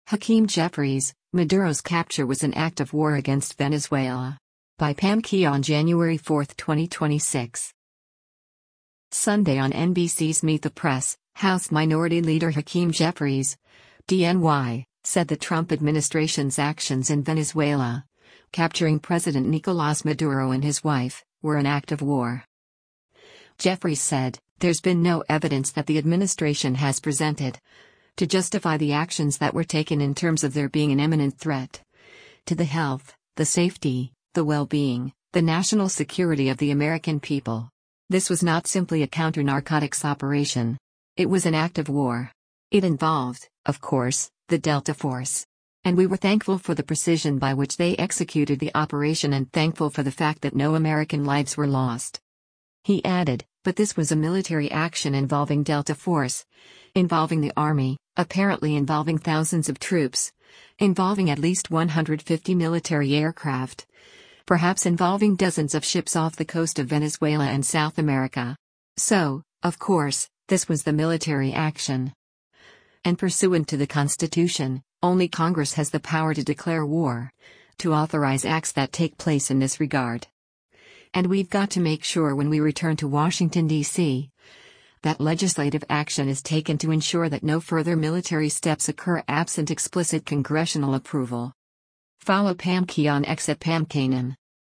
Sunday on NBC’s “Meet the Press,” House Minority Leader Hakeem Jeffries (D-NY) said the Trump administration’s actions in Venezuela, capturing President Nicolás Maduro and his wife, were an “act of war.”